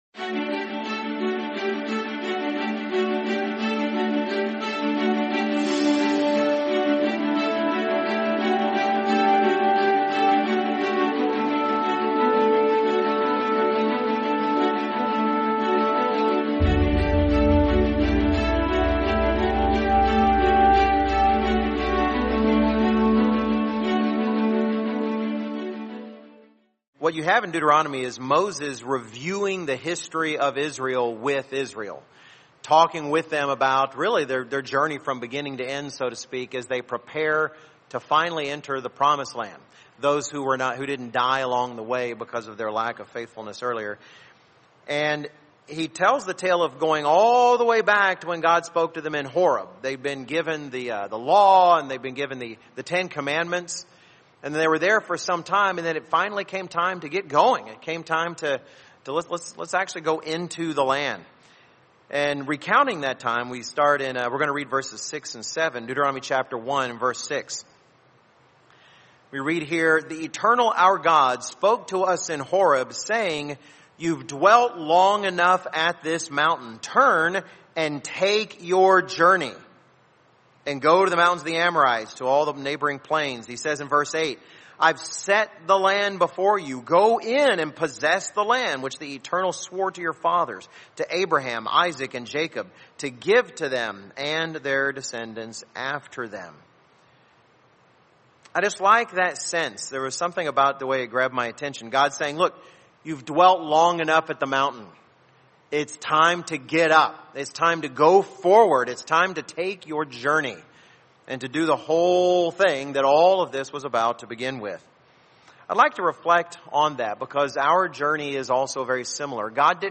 Sermon Forward!